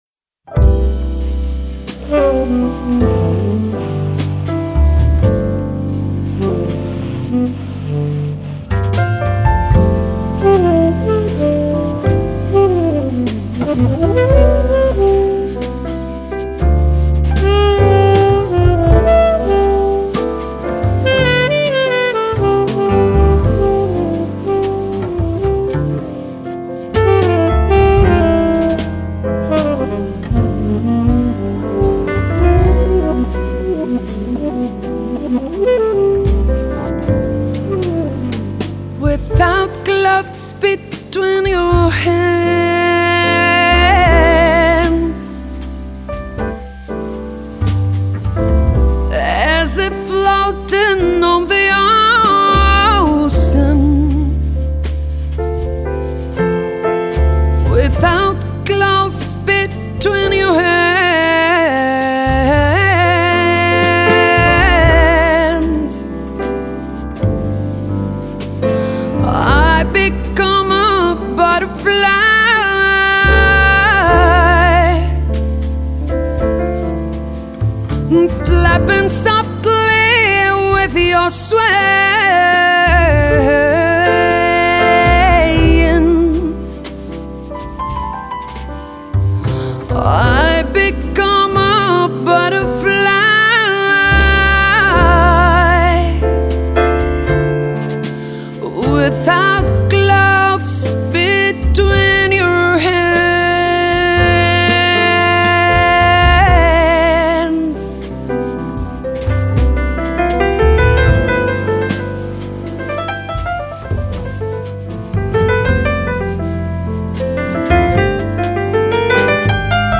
Жанр: Folk.